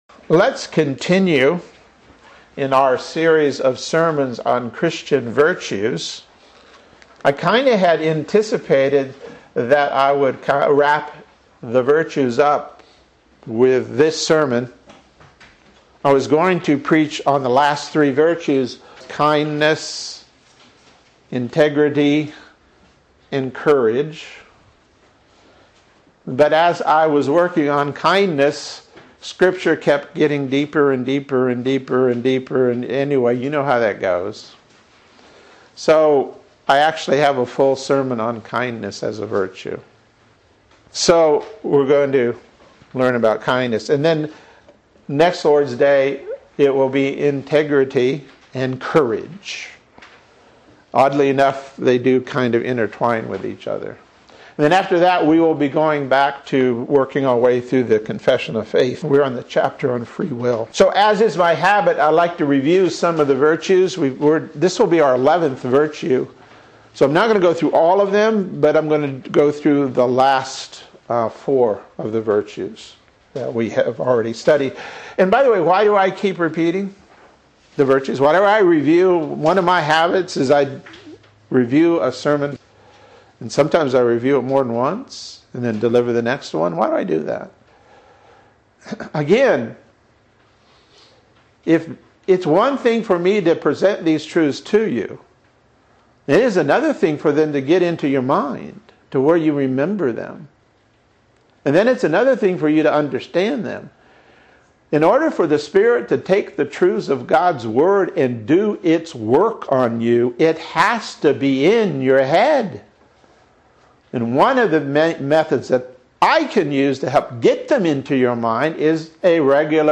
Sermons | Reformed Presbyterian Church of Ocala